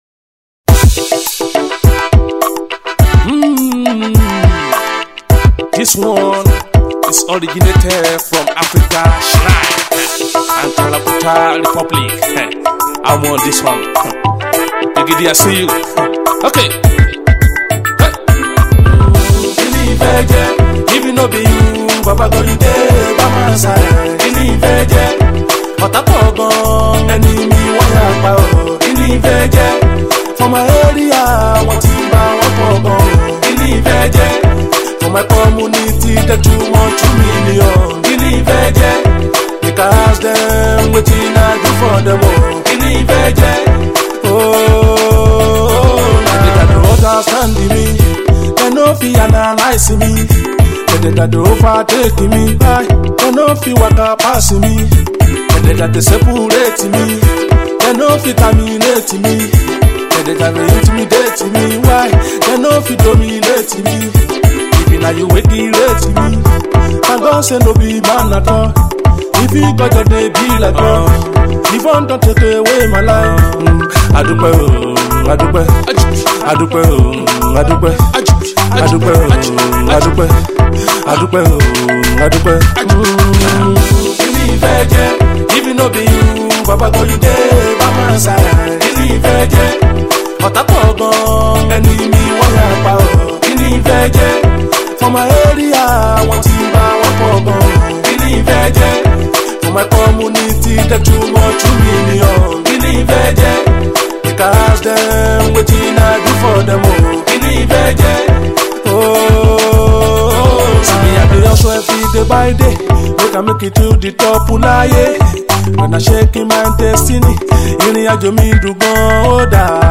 Alternative Pop
Yoruba Music